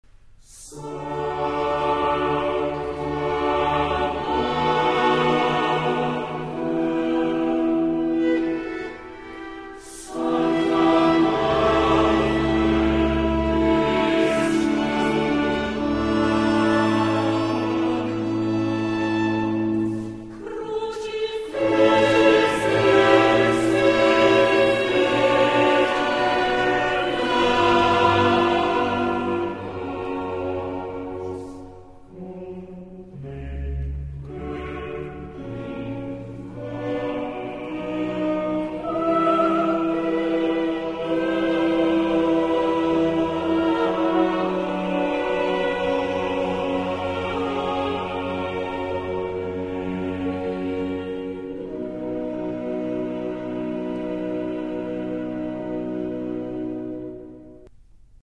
Coro della radio Svizzera - Diego Fasolis & Aura Musicale Budapest - René Clemencic
ATTENTION ! Dans cet enregistrement, on utilise le "diapason baroque" (La415).
On l'entend donc environ un demi-ton en dessous du diapason actuel (La440) que l'on emploiera et qui est celui des fichiers midi et virtual voice.